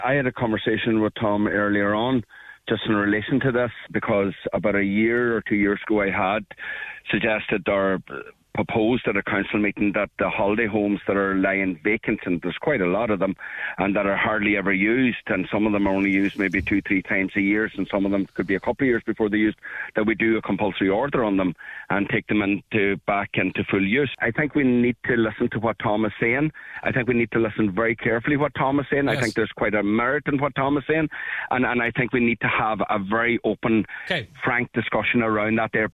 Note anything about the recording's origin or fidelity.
On today’s Nine til Noon Show he proposed CPOs be issued on vacant properties: